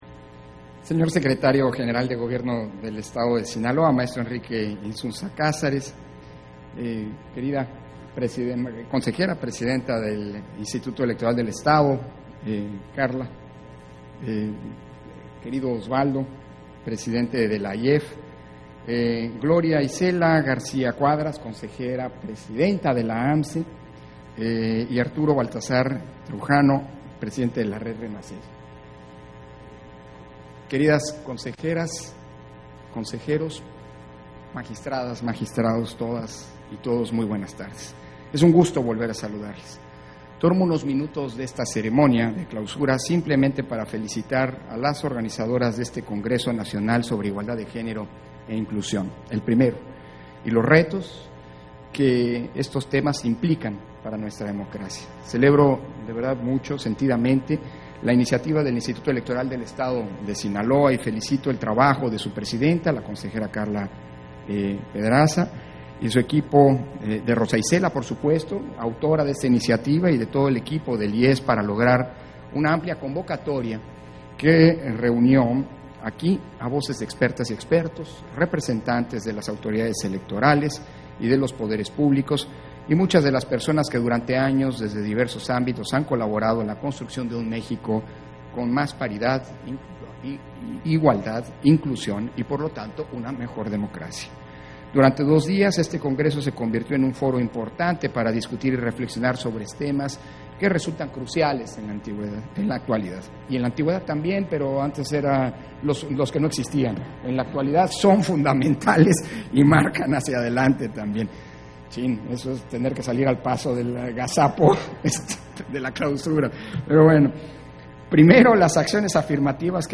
Intervención de Lorenzo Córdova, durante la clausura del 1er. Congreso Nacional de Igualdad de Género e Inclusión, Retos de la agenda democrática